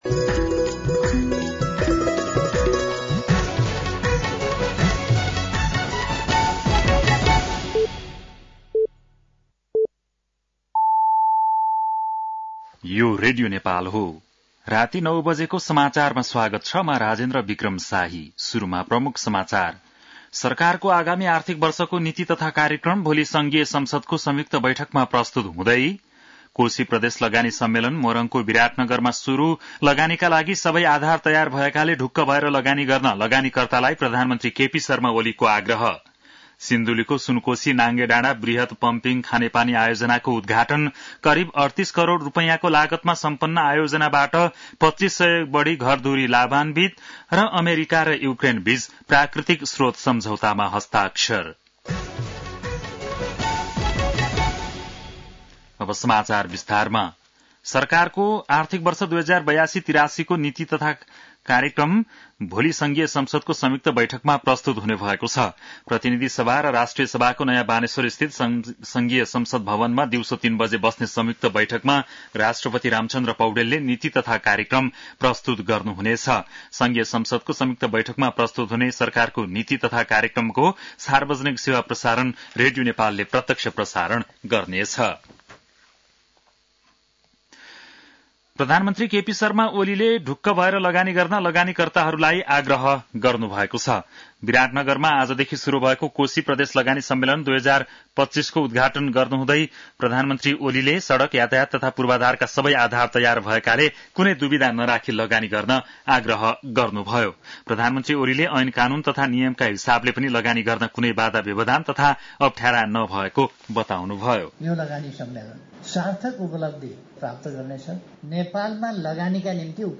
बेलुकी ९ बजेको नेपाली समाचार : १८ वैशाख , २०८२
9-PM-Nepali-NEWS-01-18.mp3